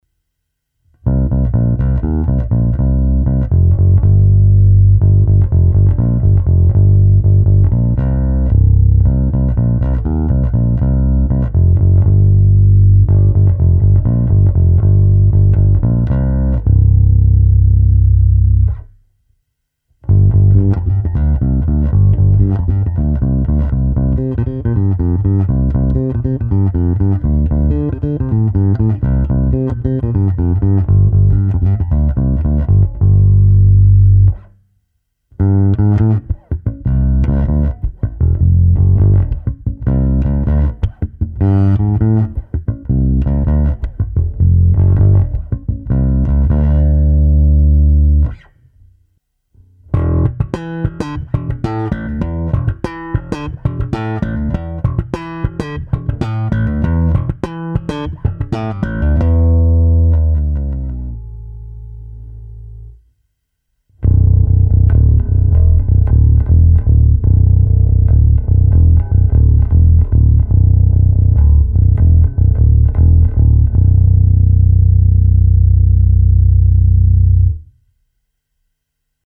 Brutálně konkrétní masívní zvuk.
Není-li uvedeno jinak, následující nahrávky jsou vyvedeny rovnou do zvukové karty a s plně otevřenou tónovou clonou, následně jsou jen normalizovány, jinak ponechány bez úprav.